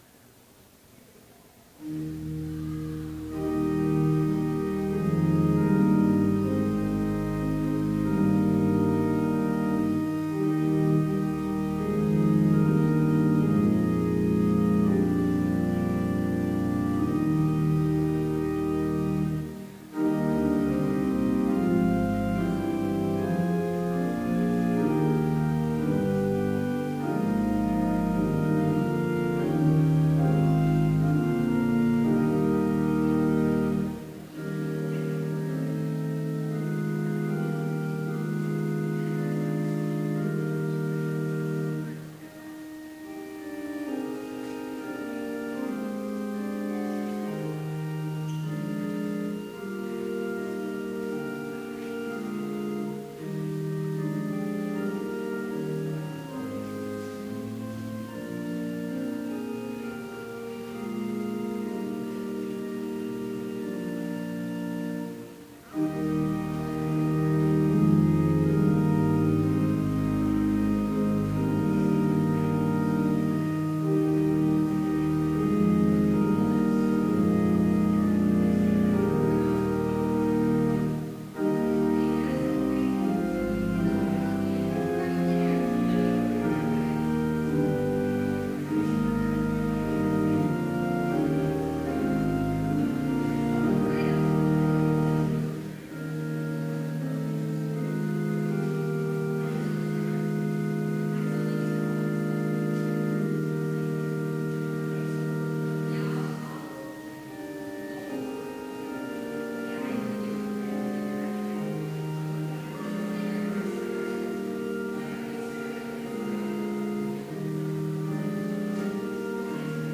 Complete service audio for Chapel - March 4, 2016